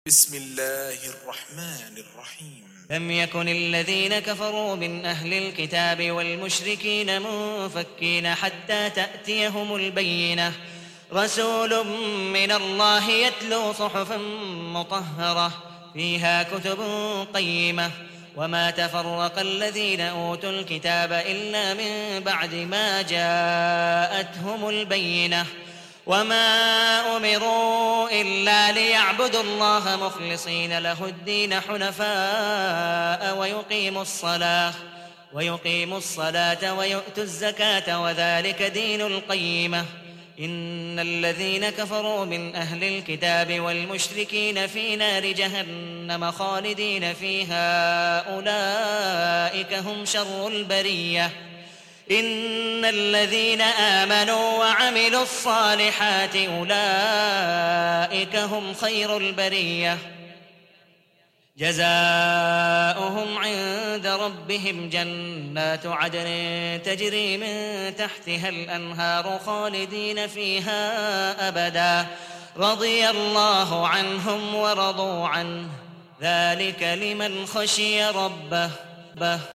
Hafs for Assem حفص عن عاصم
Tarteel المرتّلة